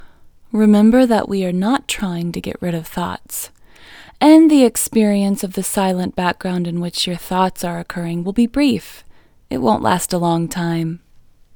LOCATE IN English Female 33